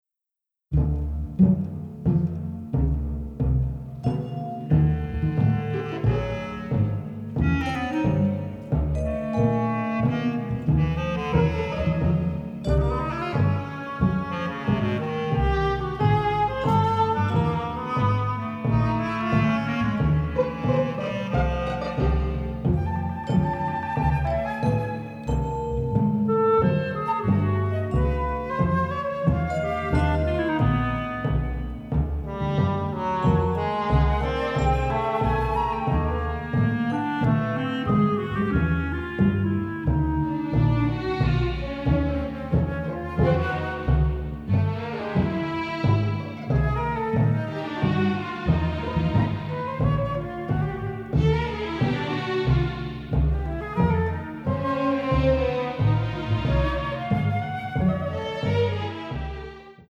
mastered from the original tapes